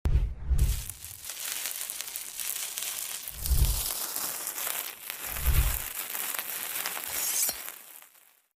Toothbrush and hot knife (ASMR) sound effects free download